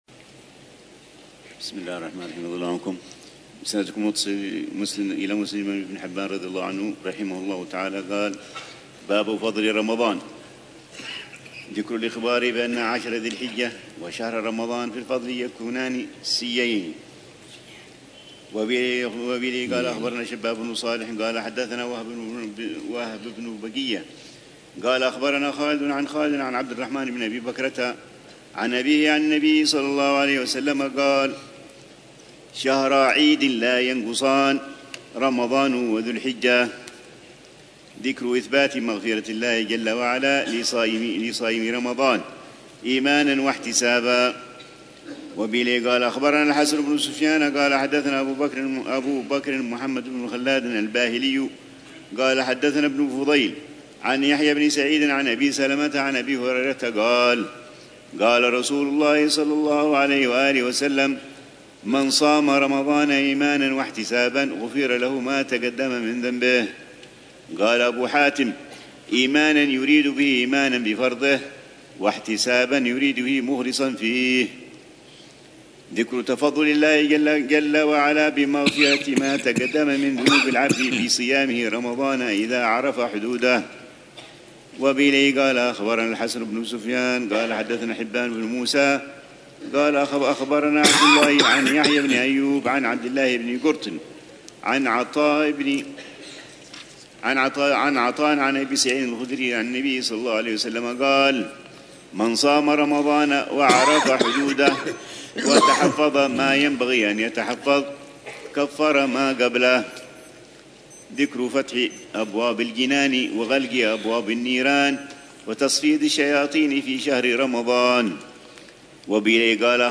الروحة الرمضانية السابعة بدار المصطفى لعام 1446هـ ، وتتضمن شرح الحبيب العلامة عمر بن محمد بن حفيظ لكتاب الصيام من صحيح ابن حبان، وكتاب الصيام